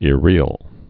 (ĭ-rēəl, -rēl, ĭr-)